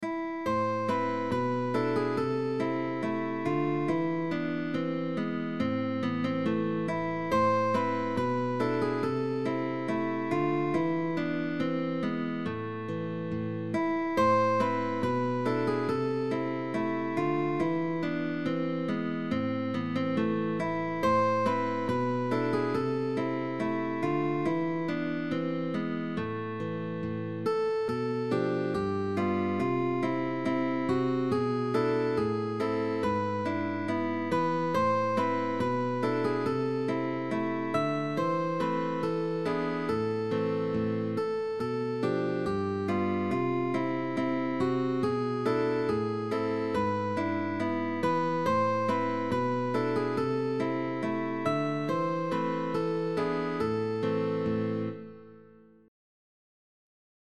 Baroque